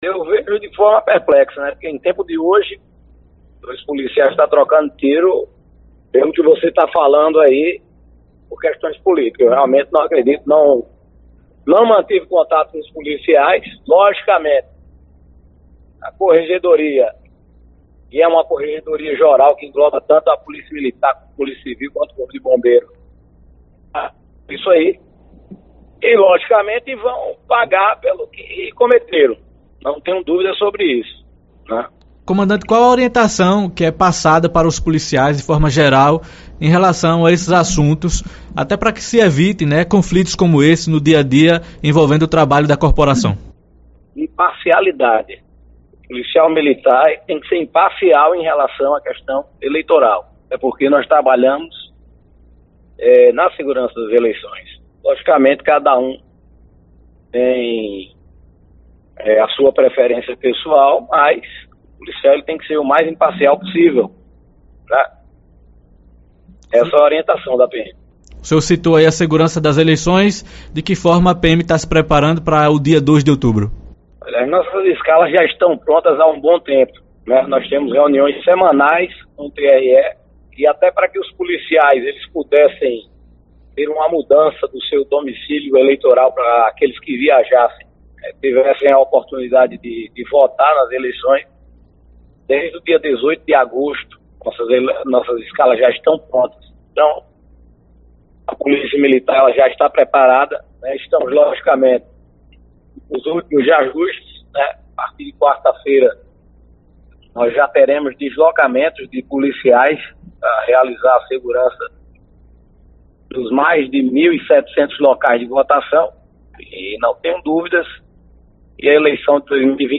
O comandante-geral da Polícia Militar da Paraíba, coronel Sérgio Fonseca, durante entrevista ao programa Arapuan Verdade, do Sistema Arapuan de Comunicação desta segunda-feira (26), comentou o episódio envolvendo dois agentes das forças de segurança do Estado que trocaram disparos de arma de fogo, em via pública, nesse fim de semana, supostamente por motivação política.